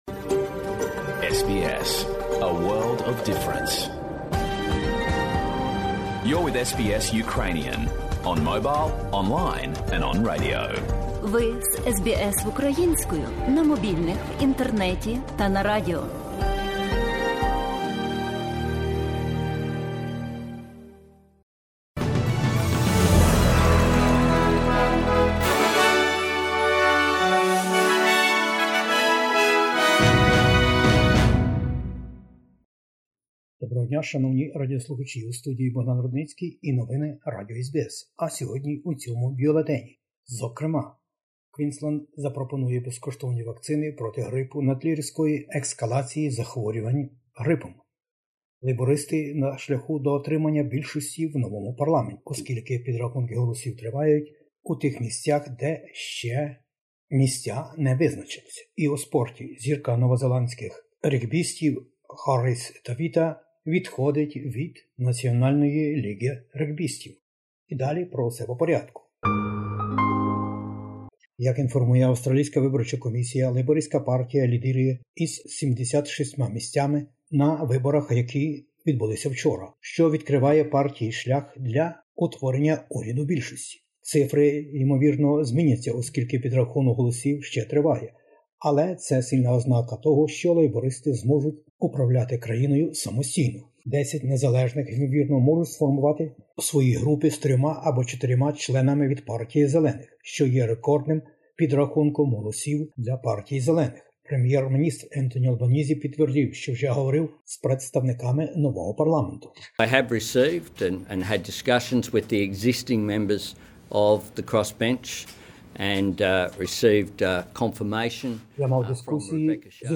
Бюлетень SBS новин українською мовою. Новий уряд опісля федеральних виборів. COVID-19 i грипові захворювання - застереження від ВООЗ.